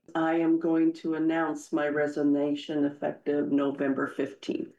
Dakota City, IA – A Humboldt County supervisor announced her intent to resign at Monday’s Humboldt County Board of Supervisors’ session.
Sandy Loney, who is the District 2 Supervisor, made her announcement during the general public comment period.